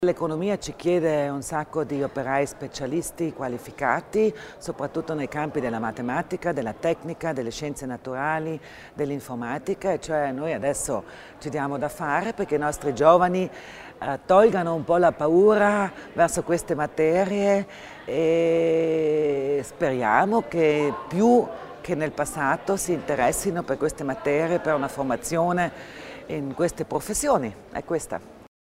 L'Assessore Kasslatter Mur spiega l'importanza della formazione tecnico-scientifica
Si è svolto questa mattina (8 febbraio) presso la sede della LUB di Bolzano il convegno MINT per la promozione delle materie tecnico-scientifiche al quale hanno preso parte l'assessora provinciale, Sabina Kasslatter Mur, Michl Ebner, presidente della Camera di commercio di Bolzano, e Walter Lorenz, rettore della Libera Università di Bolzano.